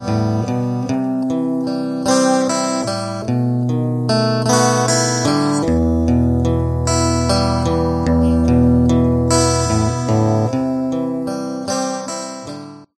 one verse section.
a simple one bar walk-up to the C
three of Em, and then walk back to the C
it’s all done as arpeggios, with some accent stuff on the high strings, and with a bit of a waltzy swing.
song 1 guitar.mp3